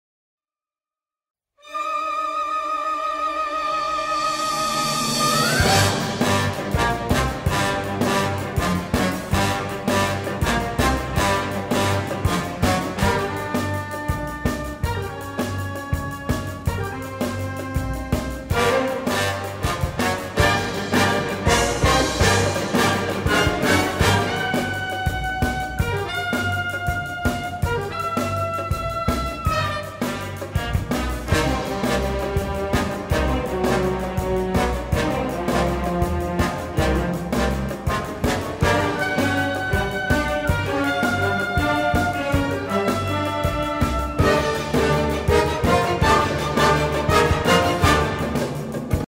LugarClub Campestre